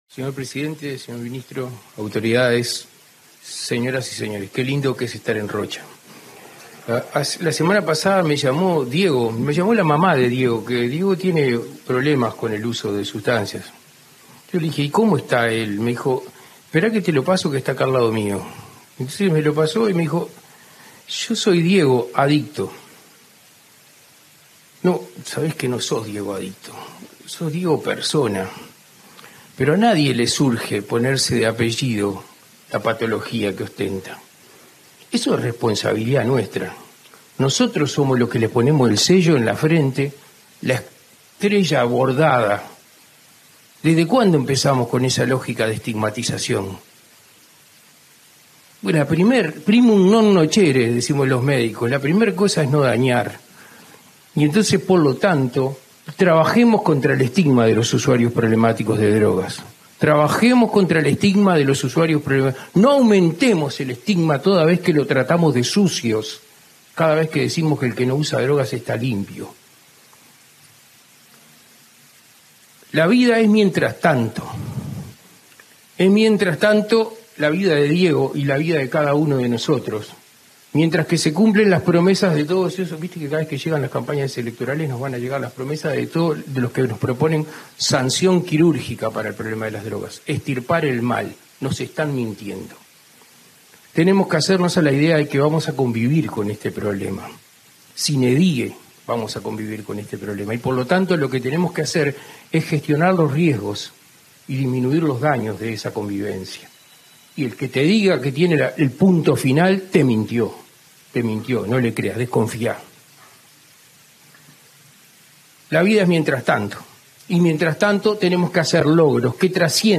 Palabras del secretario de la SND, Daniel Radío
En el marco de la inauguración de un dispositivo Ciudadela de la Secretaría Nacional de Drogas (SND) para establecer un sistema de atención para